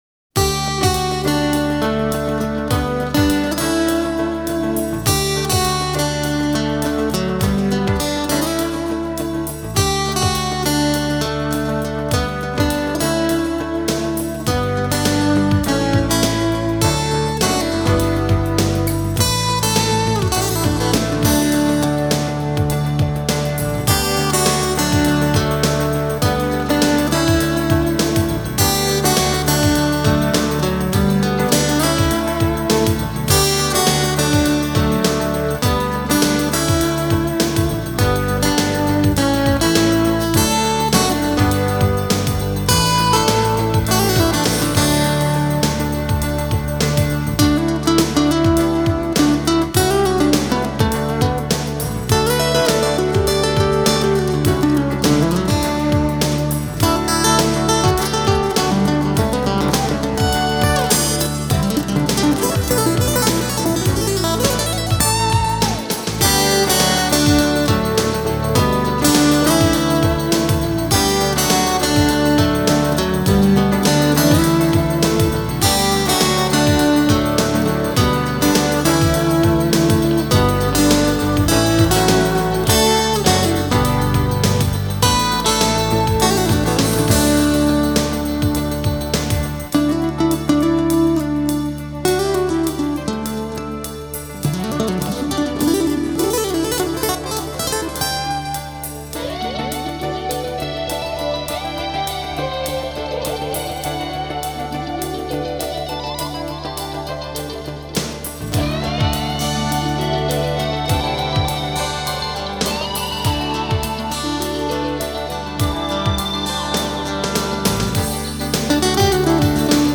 New Age or Smooth Jazz
playing guitar